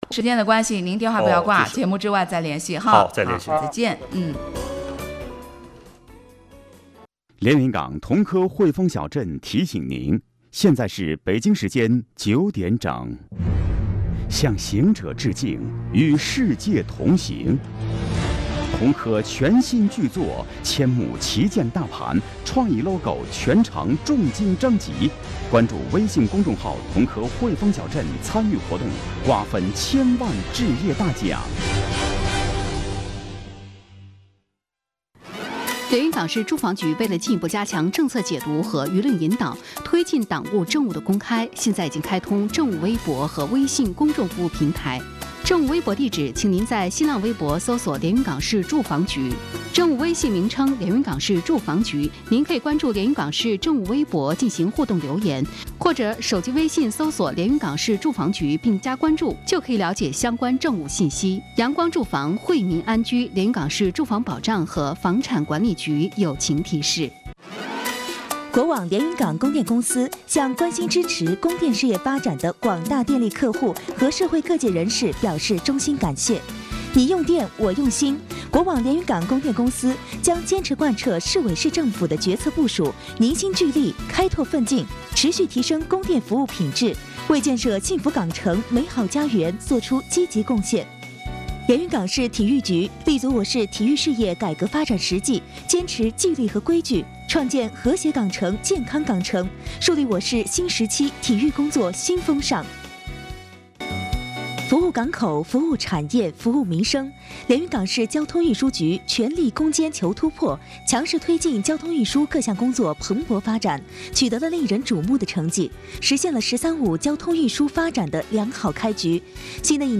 特邀嘉宾 副局长 宋和景